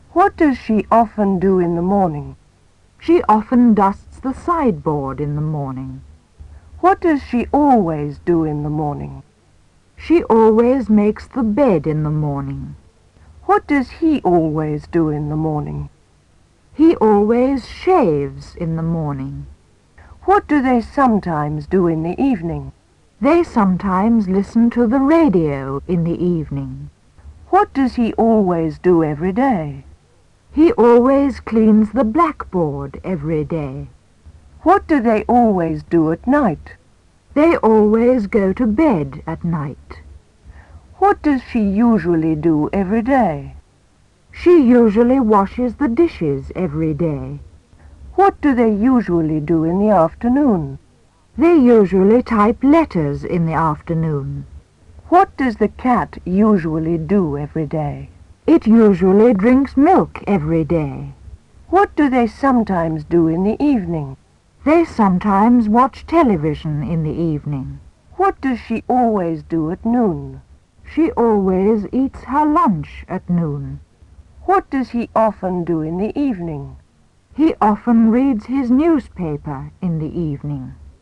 Задание 1. Прочитайте следующий диалог.
Напишите тот диалог, который произносит диктор.